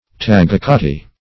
Search Result for " taguicati" : The Collaborative International Dictionary of English v.0.48: Taguicati \Ta`gui*ca"ti\, n. [From the native name.]